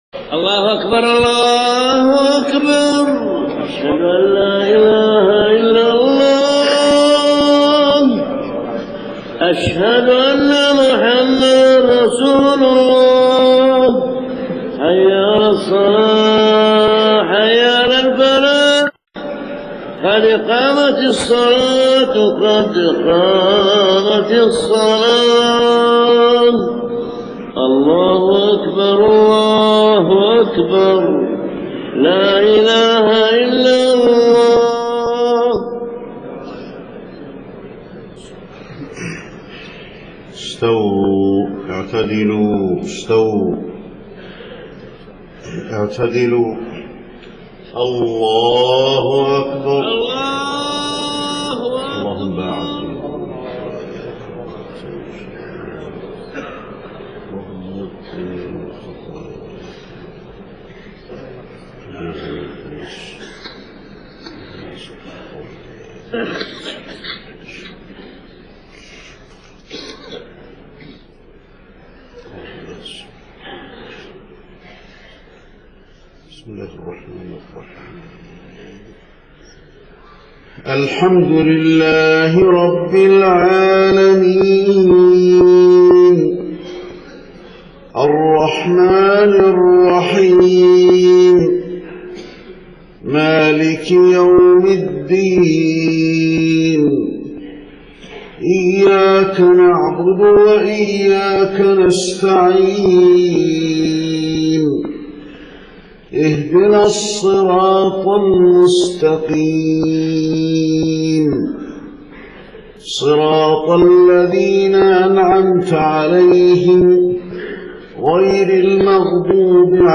صلاة الفجر 2 محرم 1430هـ سورة الصف كاملة > 1430 🕌 > الفروض - تلاوات الحرمين